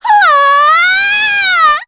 voices / heroes / en
Pansirone-Vox_Casting4.wav